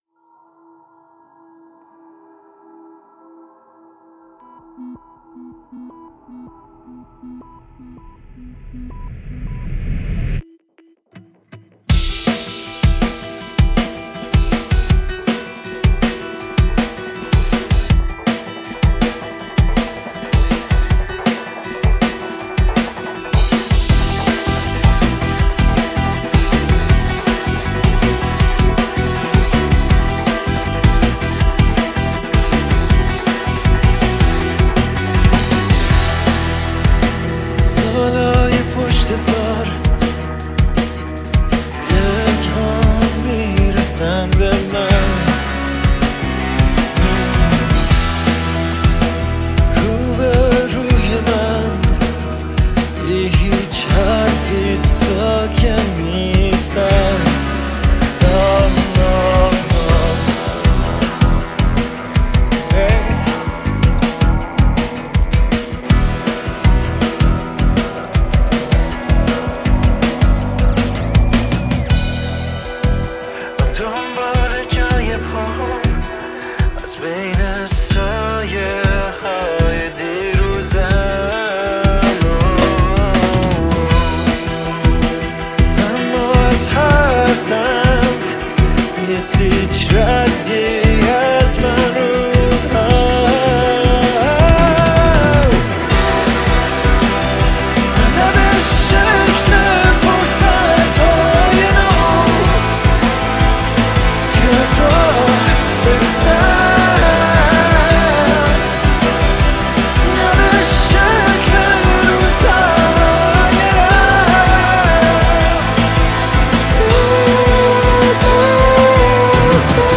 آهنگ راک
موسیقی ایرانی